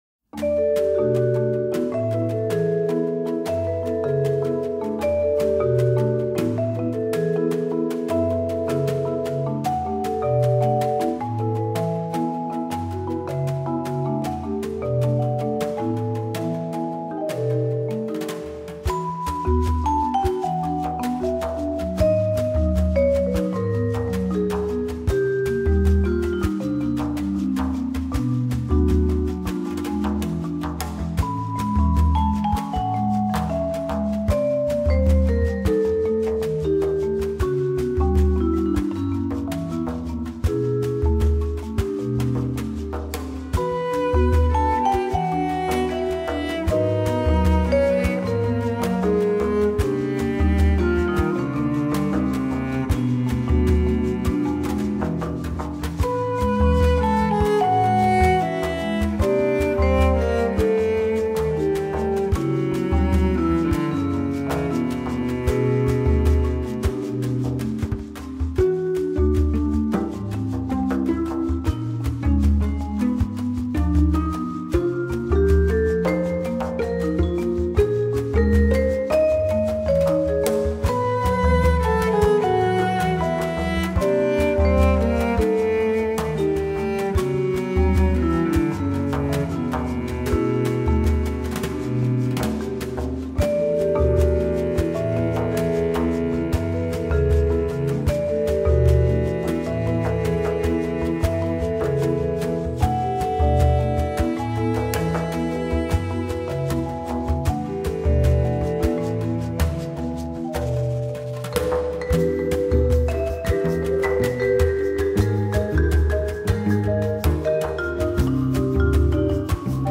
Elevator Music [Jazzy].mp3